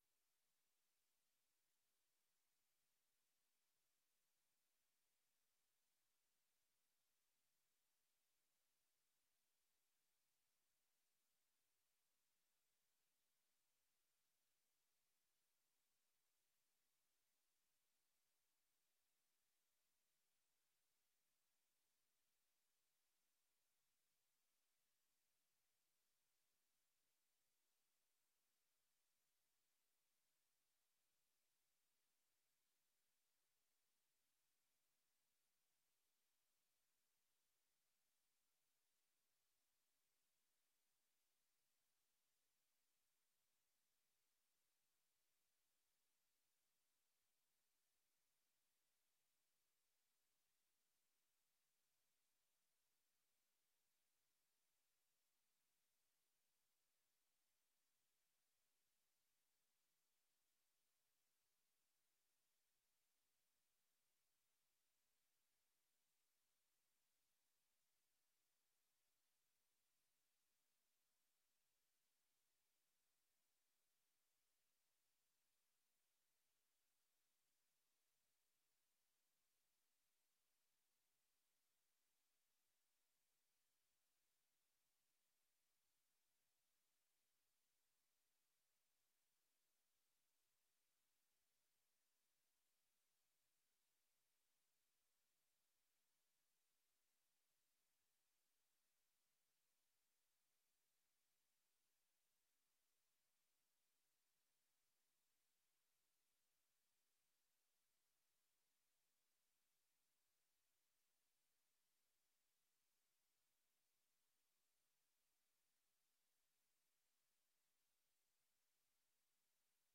Informatiebijeenkomst 19 mei 2025 17:00:00, Gemeente Den Helder
Locatie: Raadzaal